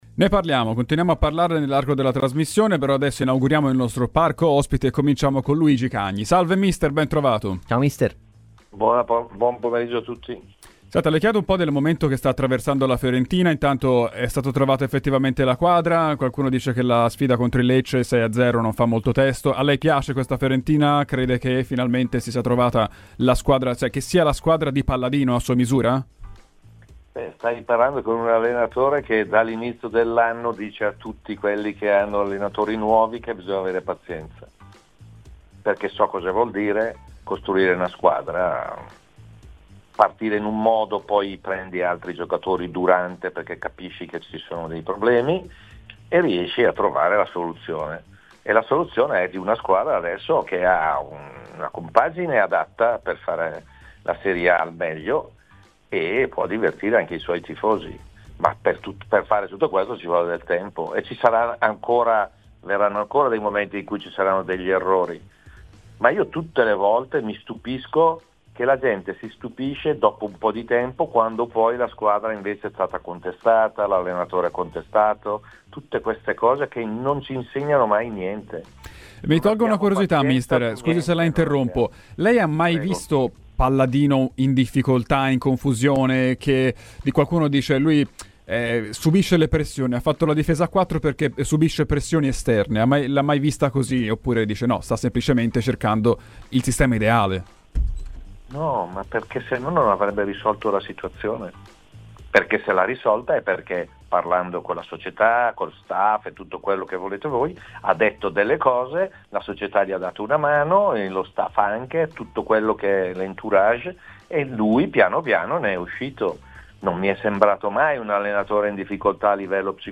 Il tecnico Luigi Cagni è intervenuto a Radio FirenzeViola durante "I tempi supplementari".